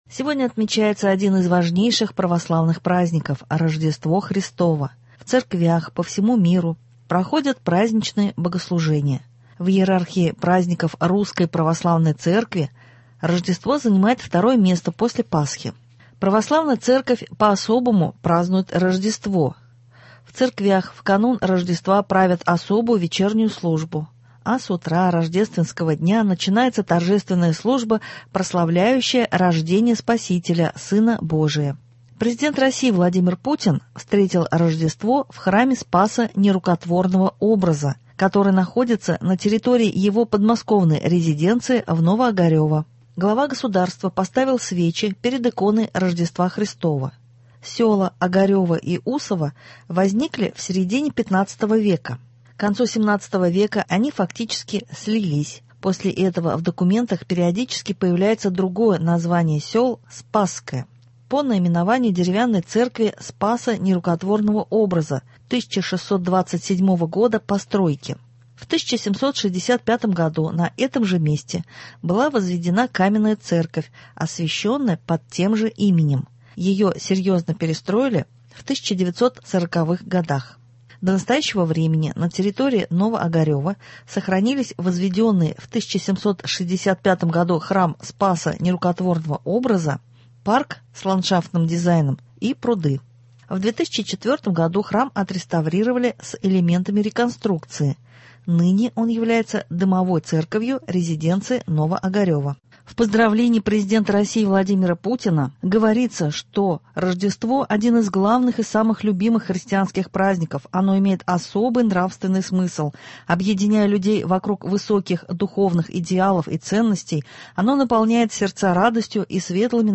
Новости (07.01.22) | Вести Татарстан